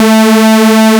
chorus.wav